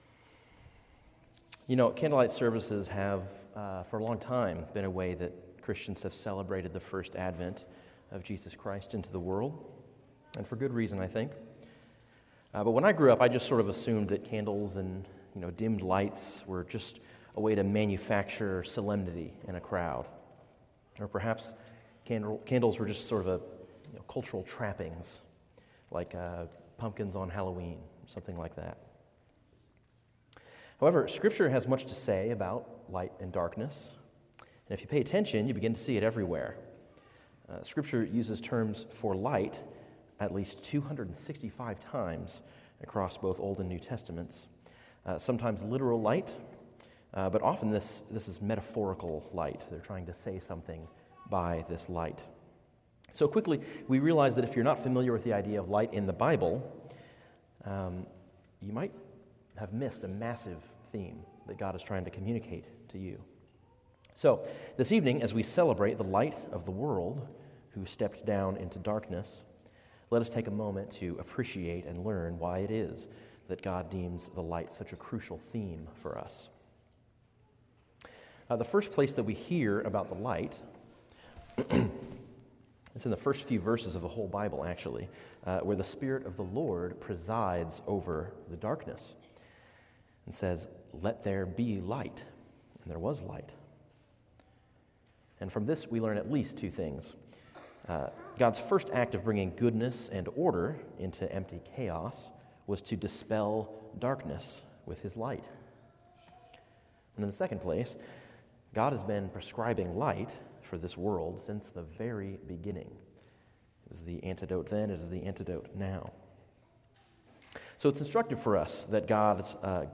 From Series: "Standalone Sermons"